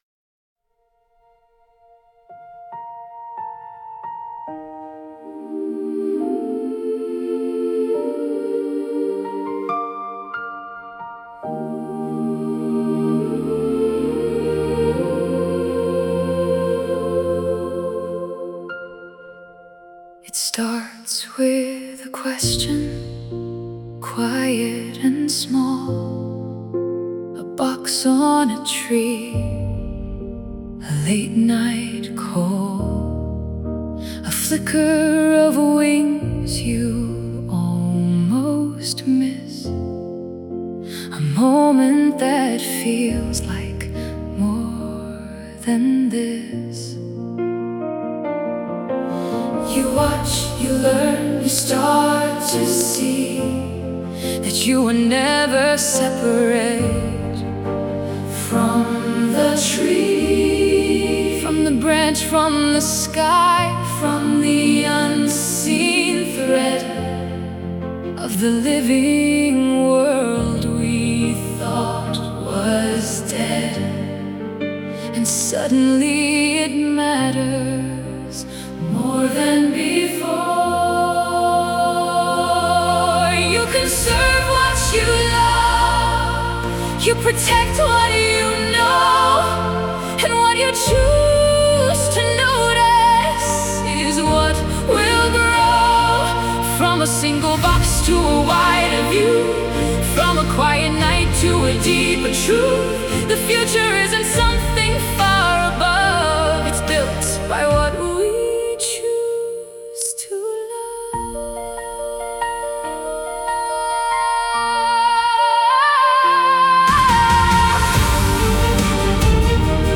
Nine songs inspired by our backyard owls and created with artificial intelligence.
A reflective closing piece about noticing, caring, and how small acts of protection shape the future for wild things.